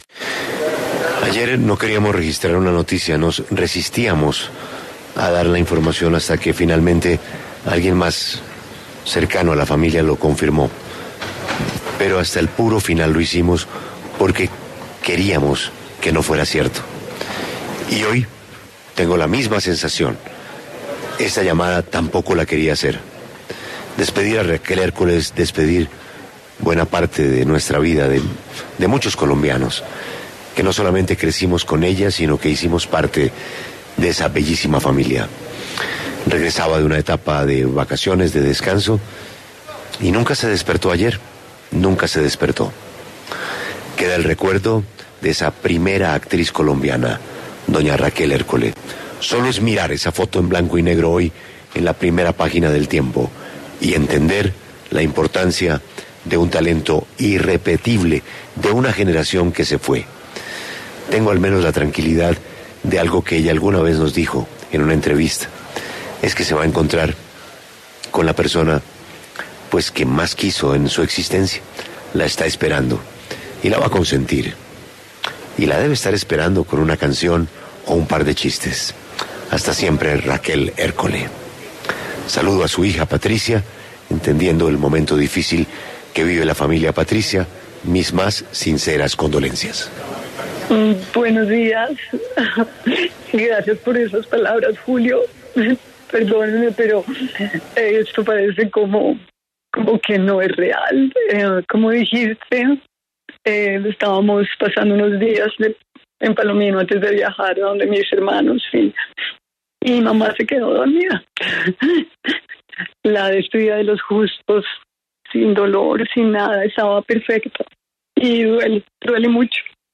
En diálogo con Julio Sánchez Cristo, Patricia Ércole habla sobre la muerte de su madre, Raquel Ércole.